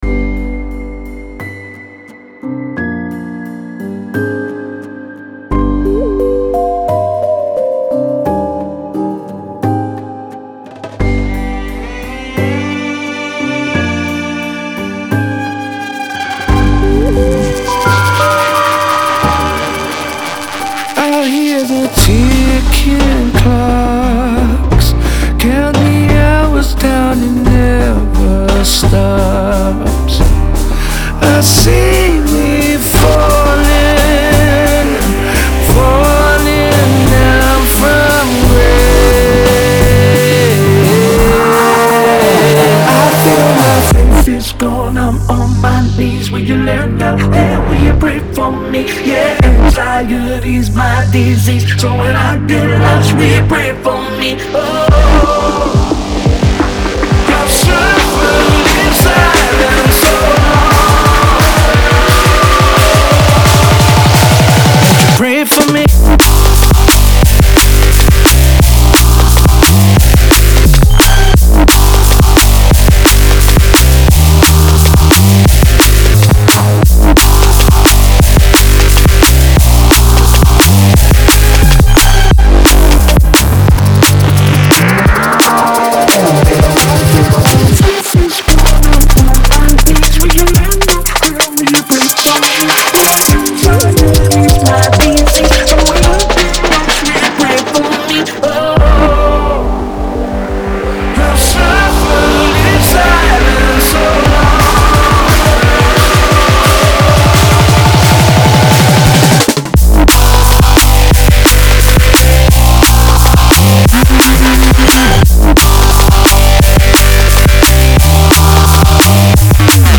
• Жанр: Dram&Bass